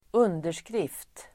Uttal: [²'un:der_skrif:t]